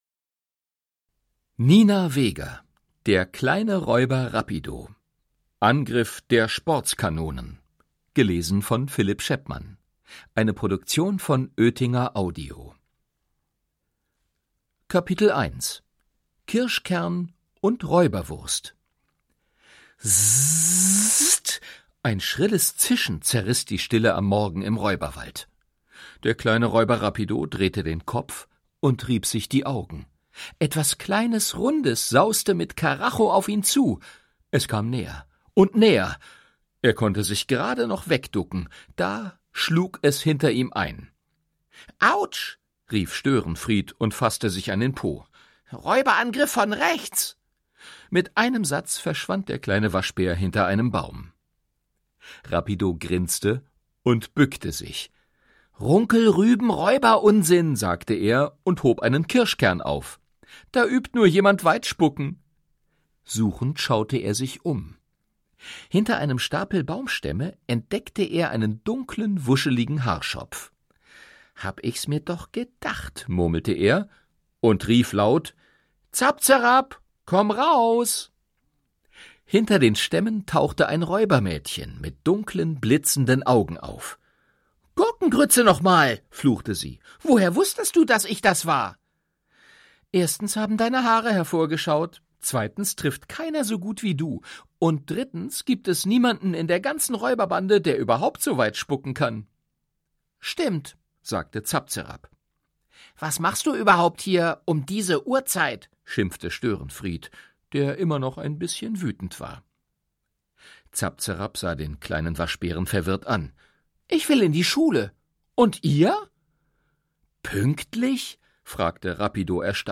Hörbuch: Der kleine Räuber Rapido 2.